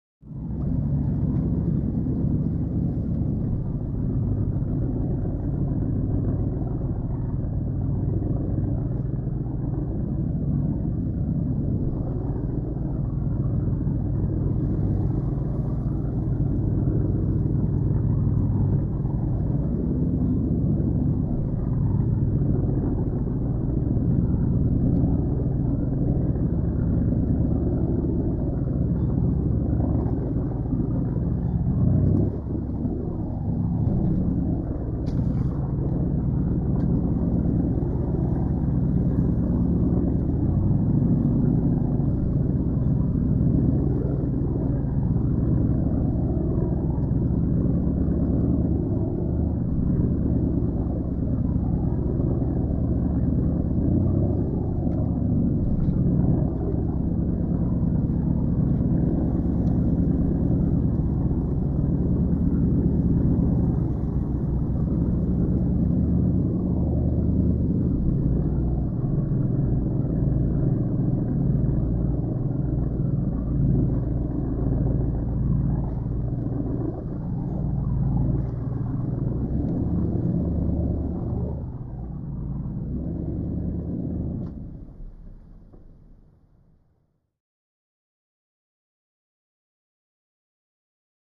Power Yacht 2; Idle Steady, Few Accelerations And Decelerations, Slight Water Splashes. Large Twin Diesel Motors.